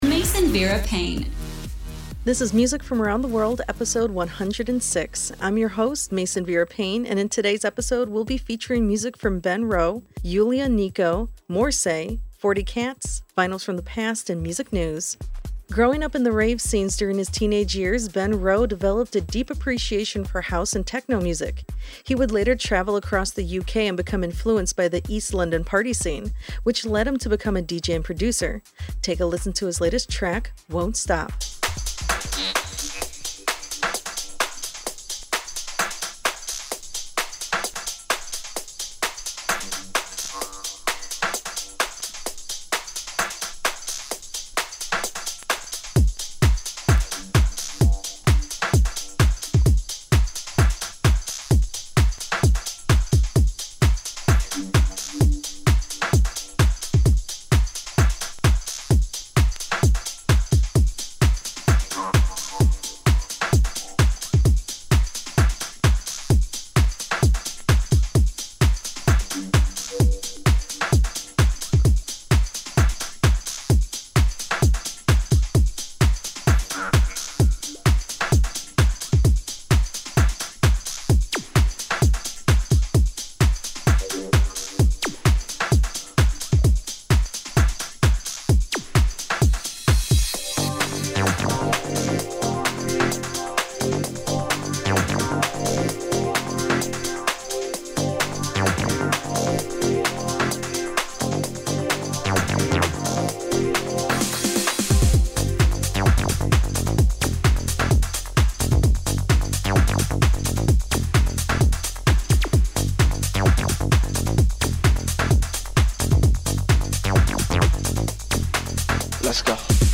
Global DJs: Techno, Indie & Psy-Trance